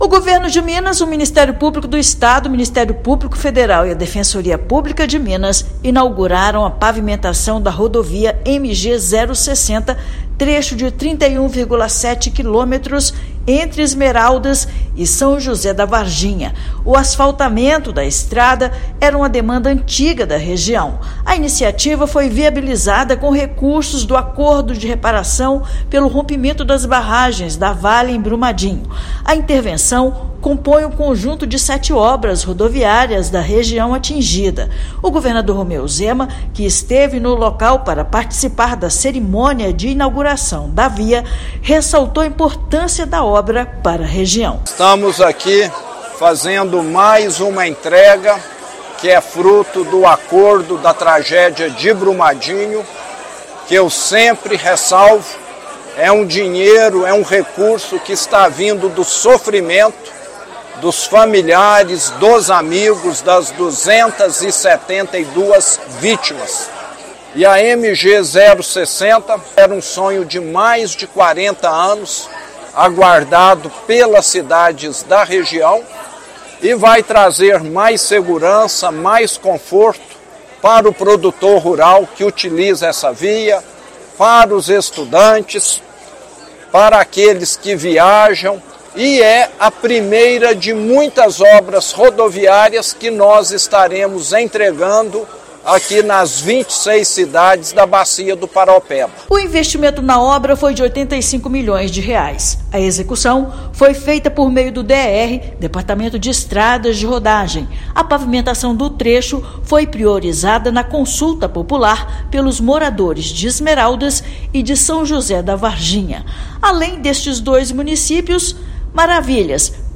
Asfaltamento da MG-060 entre Esmeraldas e São José da Varginha era uma demanda importante para o desenvolvimento da região e foi amplamente priorizada pela população dos municípios na Consulta Popular. Ouça matéria de rádio.